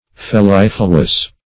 Search Result for " fellifluous" : The Collaborative International Dictionary of English v.0.48: Fellifluous \Fel*lif"lu*ous\, a. [L. fellifuus; fel gall + fluere to flow.] Flowing with gall.